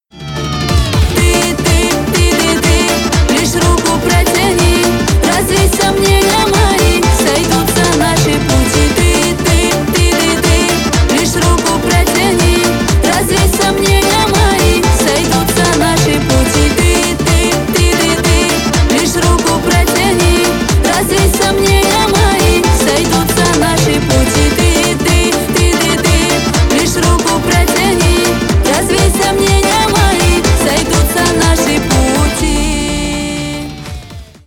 Нарезка припева на вызов